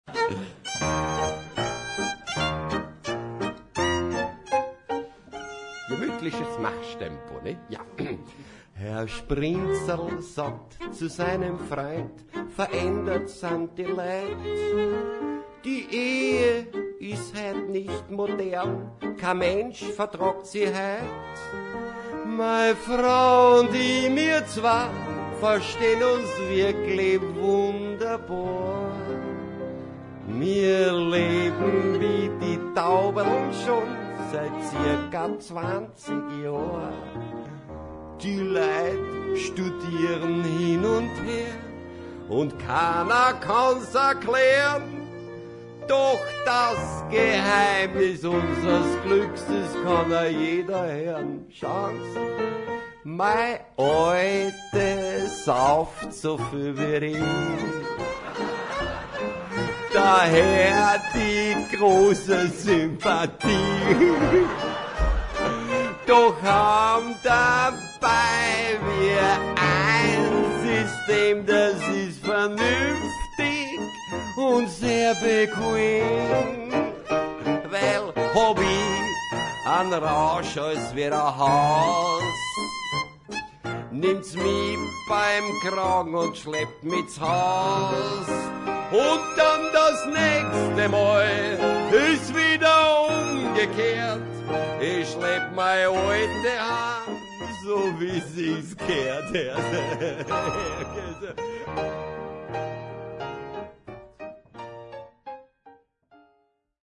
unsere rheinisch-japanische Pianistin